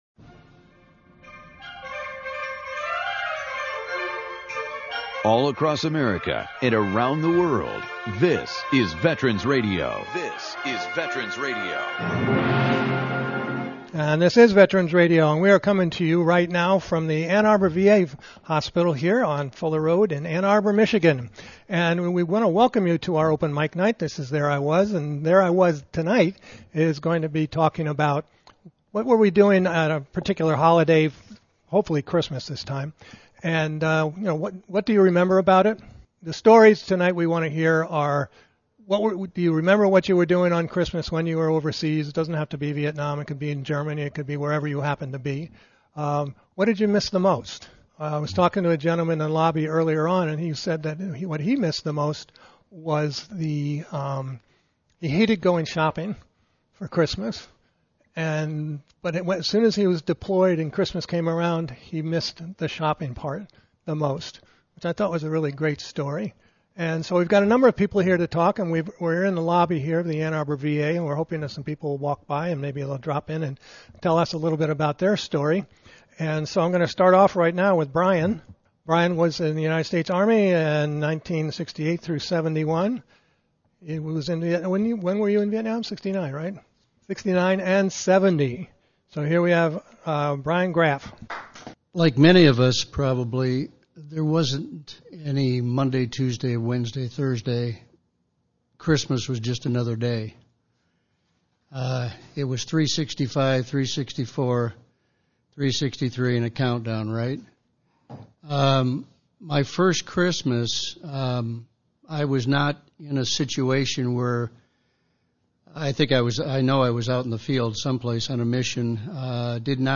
“There I Was…” is an open mic program where veterans tell their stories of service to the United States military - all branches, all levels of command.
You will hear from Army, Navy, Air Force, Coast Guard, and Marines. Their reflections on that time of their life provide us with a fresh perspective on the many facets of military service as well as life after the military.
This week’s program comes to you from the Ann Arbor Michigan VA Hospital.